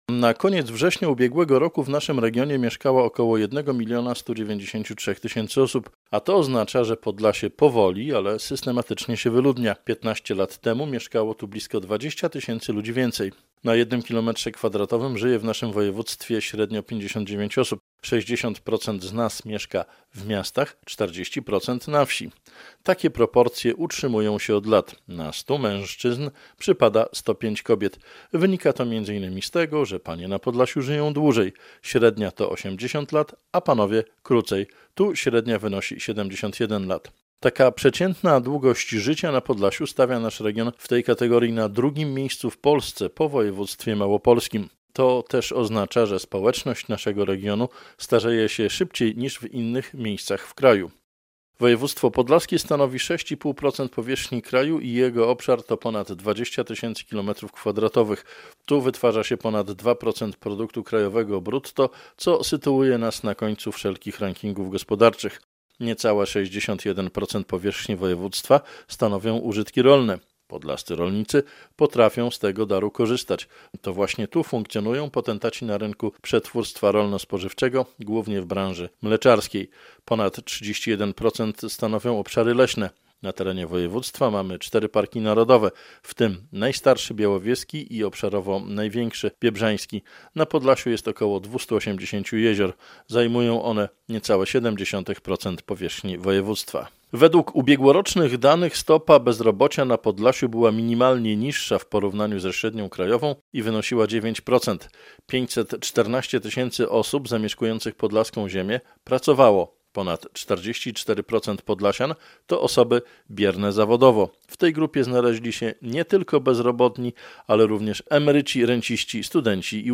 Dzień statystyki polskiej - komentarz